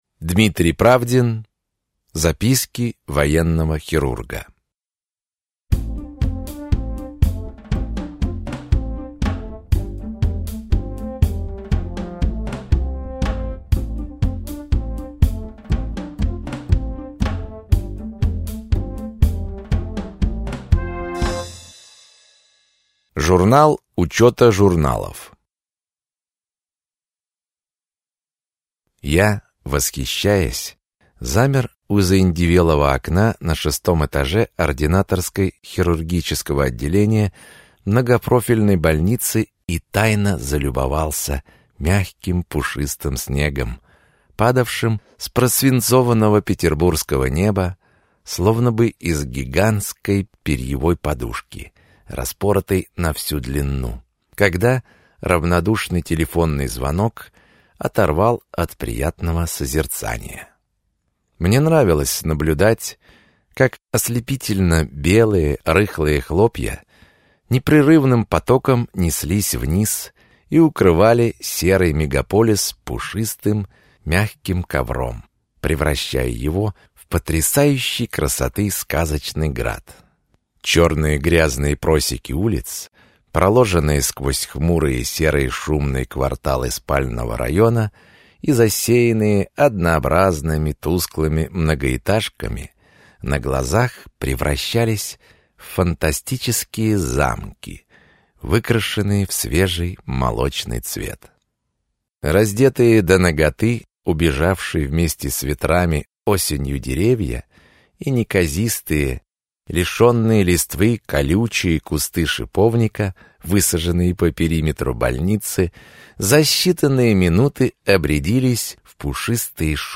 Аудиокнига Записки хирурга военного госпиталя | Библиотека аудиокниг